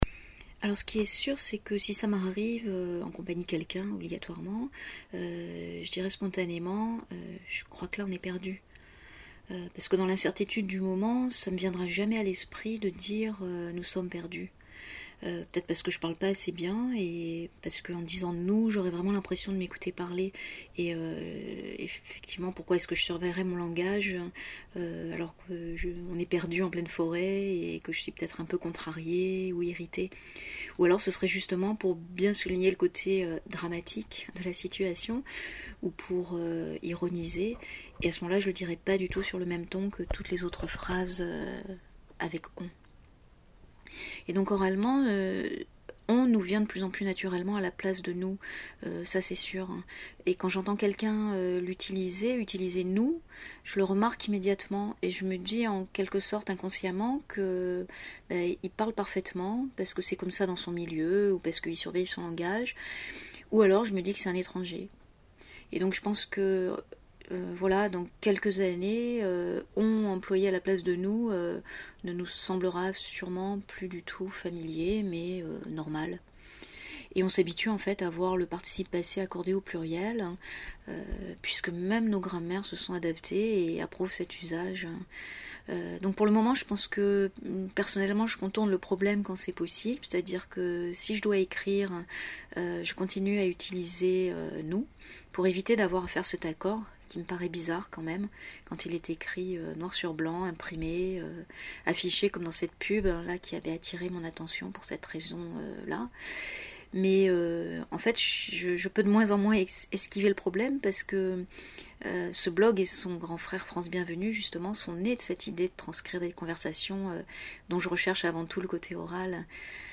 Comme j’ai d’abord pensé cet article à l’écrit et pas comme un enregistrement, il y a de petites différences de style parce que je ne l’ai finalement pas lu à voix haute mais « reformulé » spontanément comme si je vous parlais. Alors, il y a des Bah, des Voilà, des négations imparfaites qui traînent !
perdus-version-orale.mp3